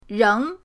reng2.mp3